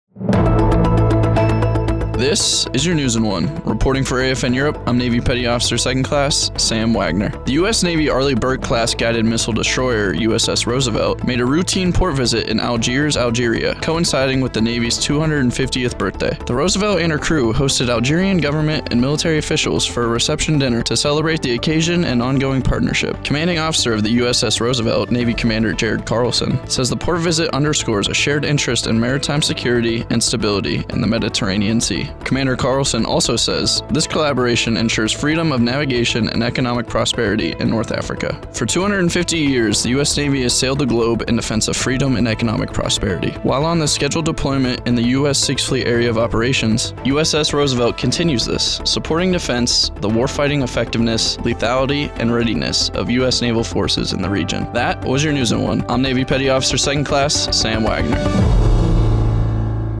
Regional Media Center AFN Europe